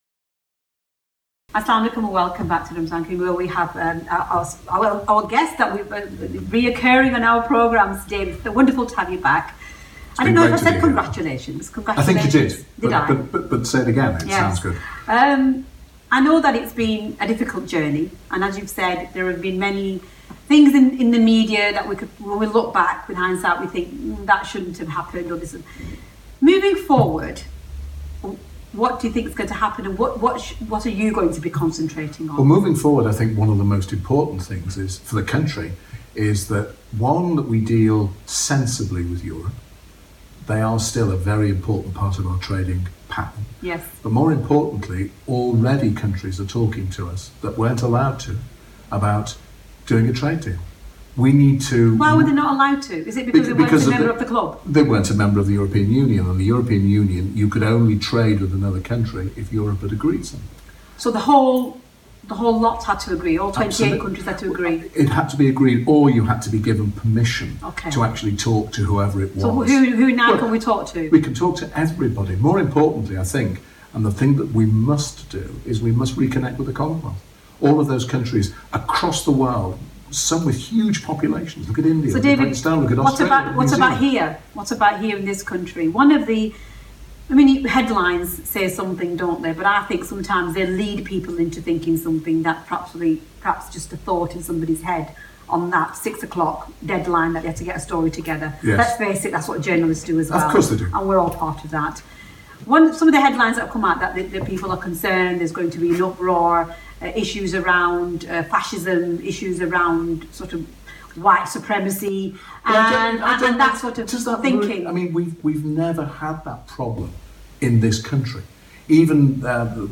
He was very active in the Leave campaign – and here he is interviewed about the campaign and what follows now that the UK is on its way out of the EU.